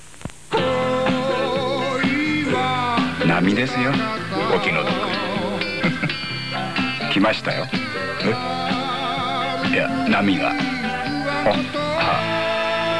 All sounds in WAV format are spoken by Yusaku himself (Also Andy Garcia and Michael Douglas).
TV ad for light beer. Two guys are sitting on the chair on the beach.